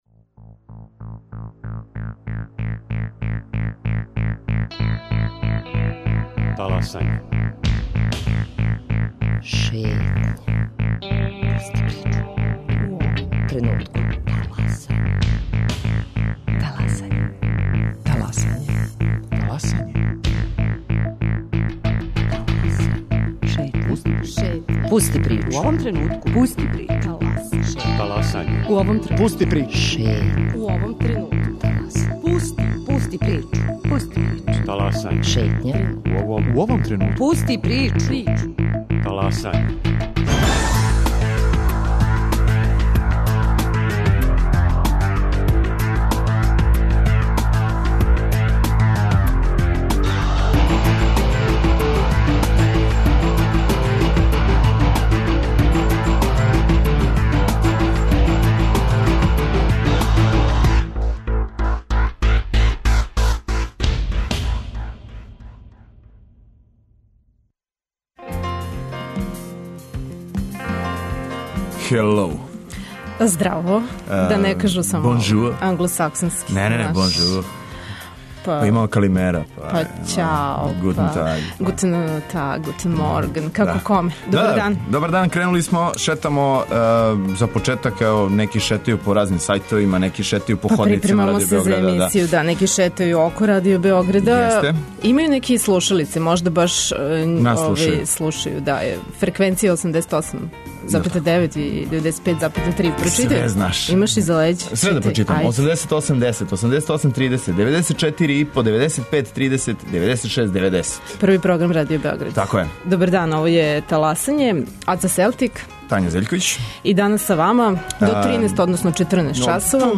И на овом једносатном путовању Европом, пратиће их пажљиво одабрана музика!